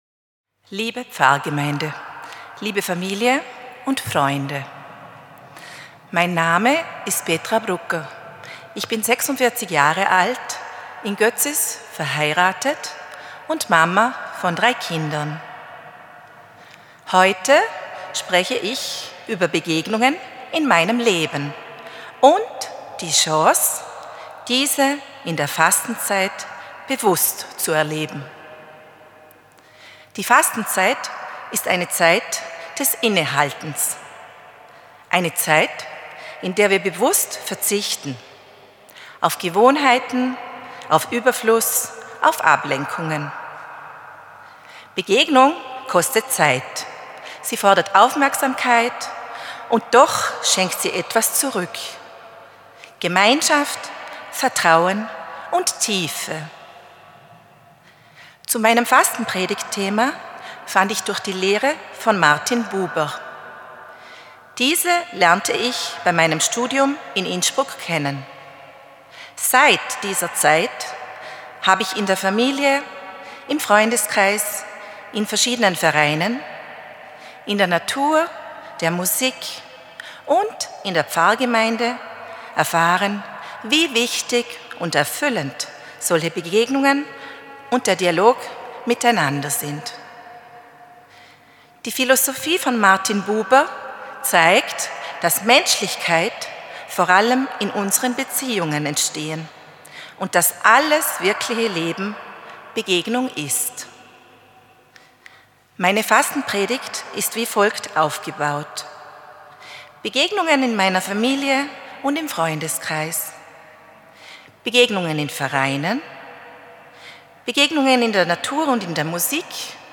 Fastenpredigt | hören - lesen